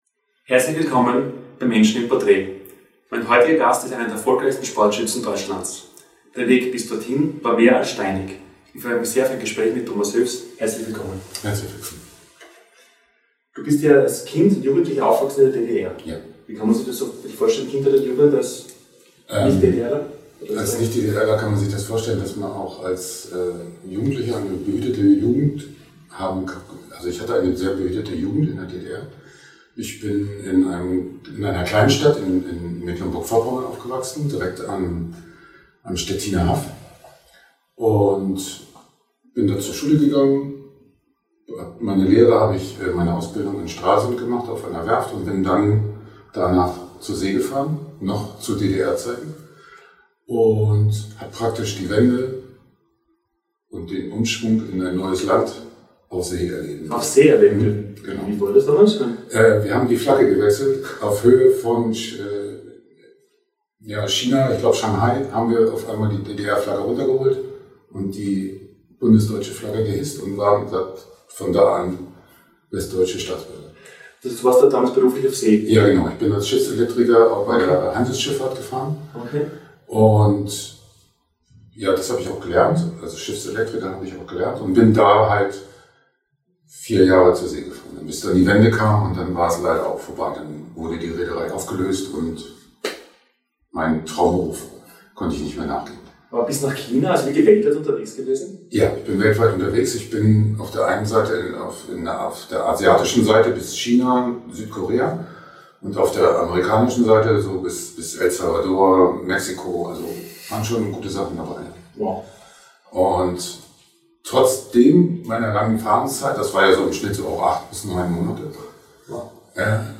Er stellte sich den großen Herausforderungen in der Disziplin "Para-Trap-Schießen" und stieg mit unglaublicher Disziplin und Ausdauer in die Weltspitze auf. Ein inspirierendes Interview mit einem absoluten Ausnahmesportler!